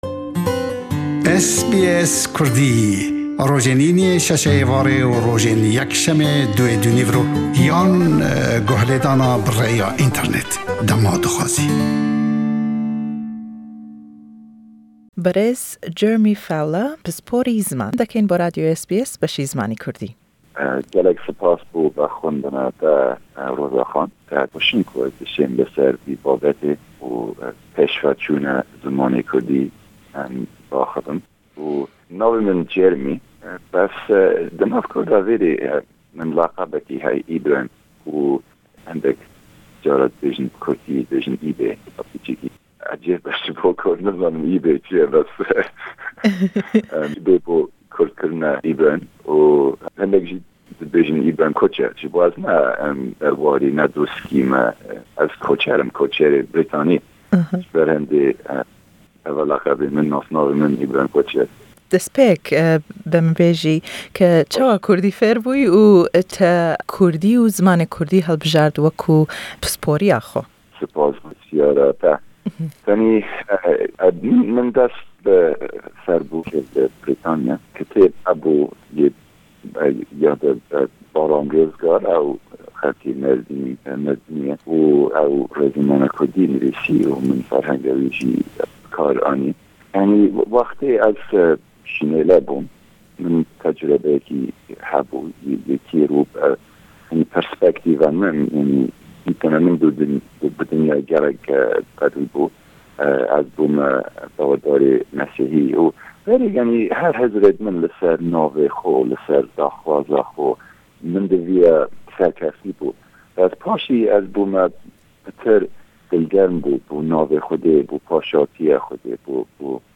Le em hevpeyvîne da bas le projeyekî zimanî Kurdî dekeyn